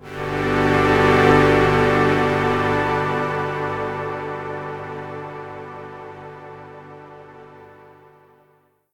Acorde musical introductorio
armonía
música instrumental
sintetizador
Sonidos: Fx web